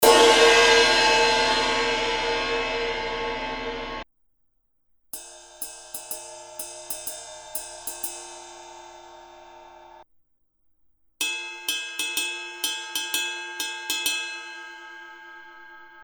It will punch through, with a bright, crisp ping, no matter how many guitarists you have.
• Style: Heavy
The concentration of peaks in the 5 kHz – 9 kHz area is what gives this cymbal its sharp ping sound.
The bell sound is pretty good, but I have heard other Zildjian ride cymbals whose bell sounds I like better.
The ping on this cymbal decays at about the same rate as the Zildjian 22″ A Custom Ping Ride Cymbal.
Click HERE to listen to an audio sample, which will include crash (when appropriate), ride, and bell sounds (these are 24 bit, 176.4 kHz wav files, so be sure your sound card is capable of handling these high resolution sound files).
zildjian-22-inch-z3-rock-ride-crash-ride-bell.mp3